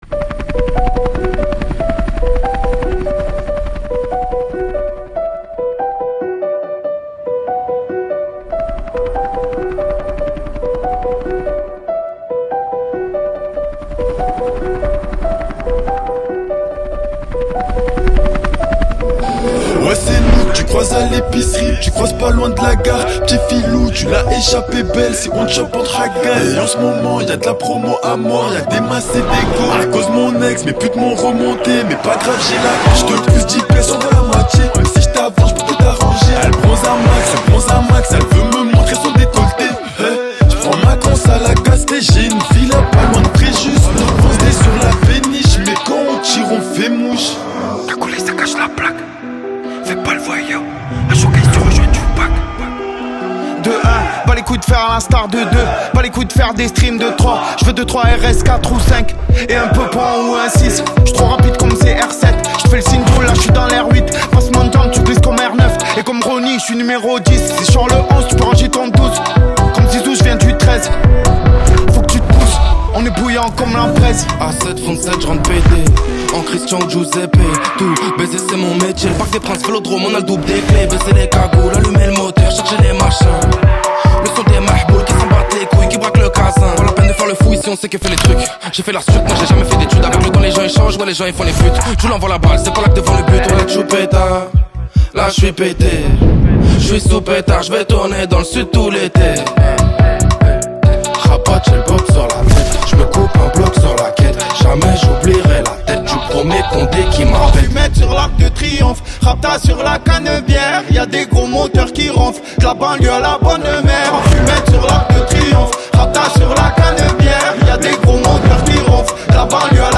4/100 Genres : french rap, french r&b Télécharger